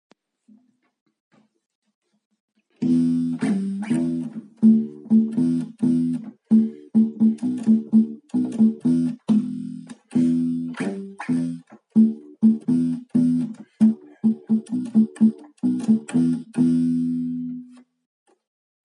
Bassriff-Quiz